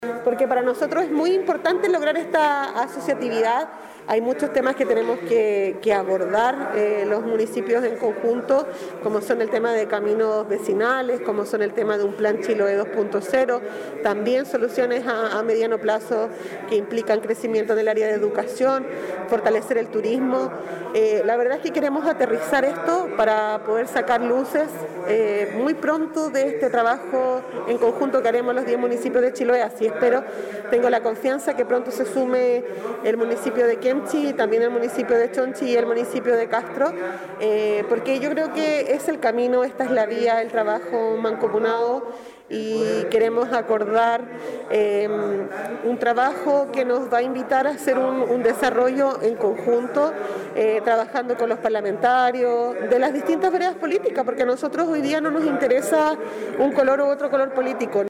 Javiera Yáñez, alcaldesa de Curaco de Vélez, manifestó además la relevancia que para la próxima sesión estén presentes todos los jefes comunales, luego que estuvieron ausentes los alcaldes de Quemchi, Castro y Chonchi.